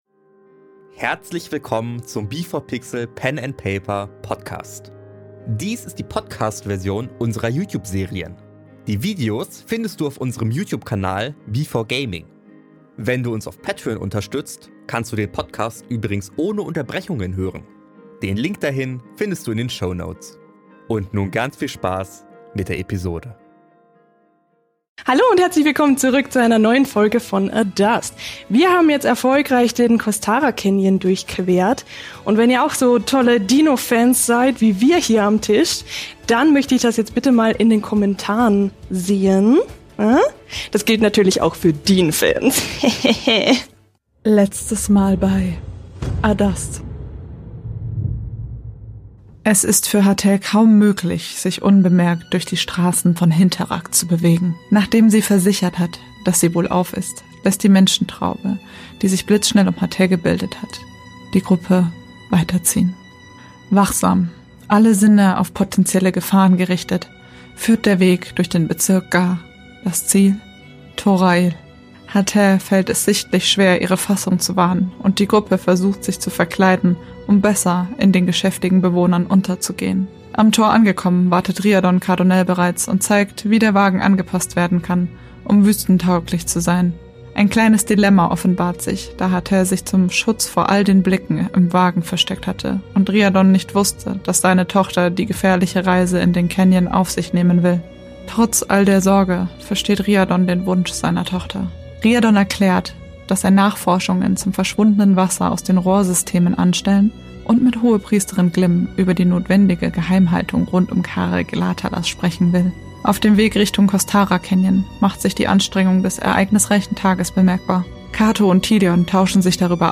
Dies hier ist die Podcast-Version mit Unterbrechungen.